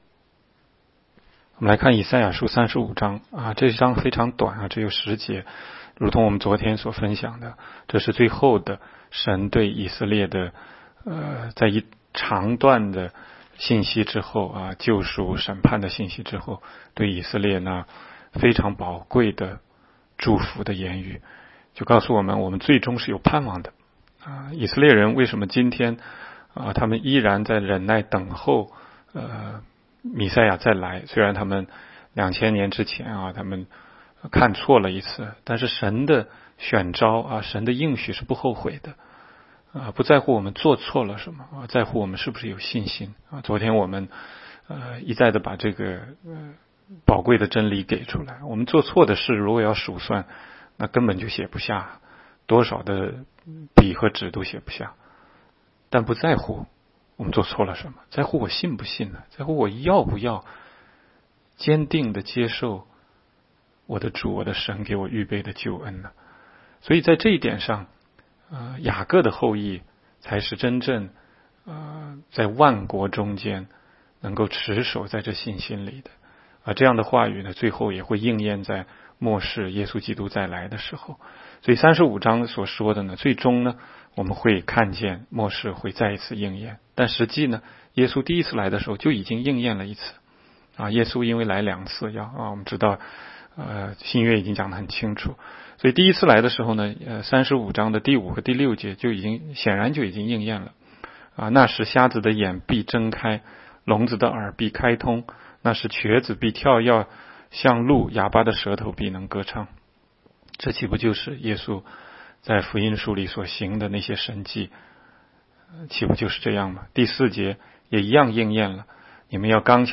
16街讲道录音 - 每日读经 -《 以赛亚书》35章